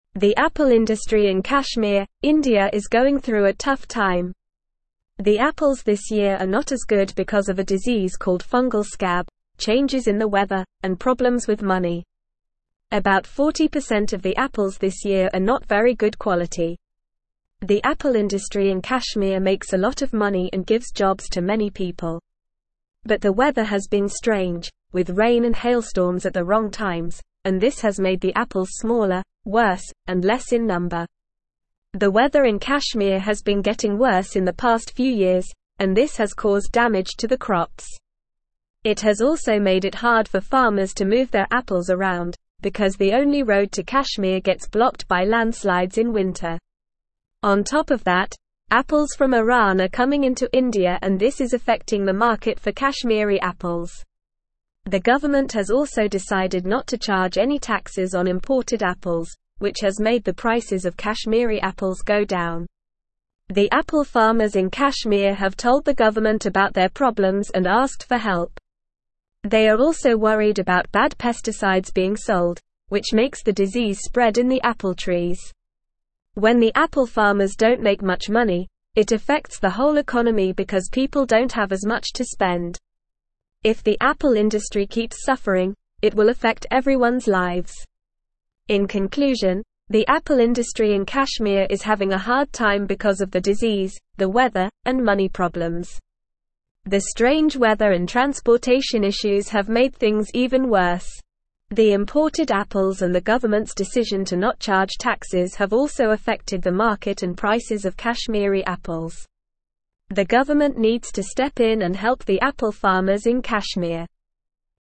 Normal
English-Newsroom-Upper-Intermediate-NORMAL-Reading-Crisis-in-Kashmirs-Apple-Industry-Challenges-and-Implications.mp3